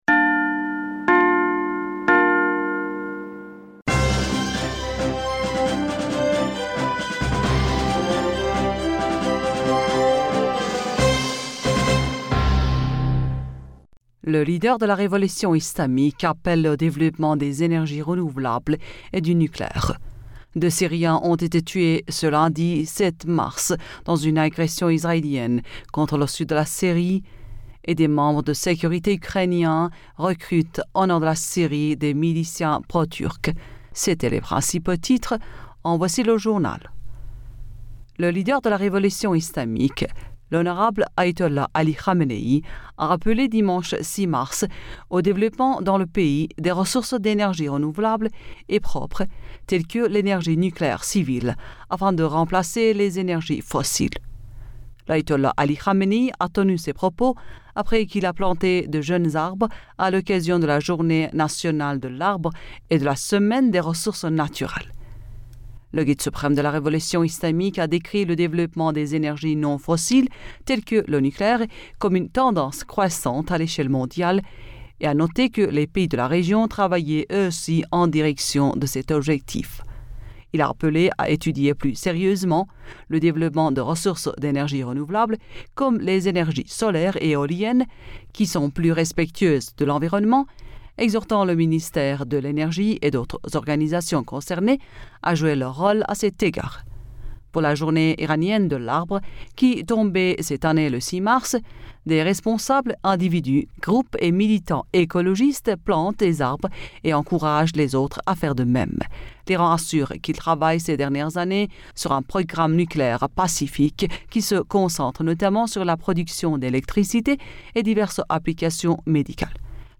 Bulletin d'information Du 07 Mars 2022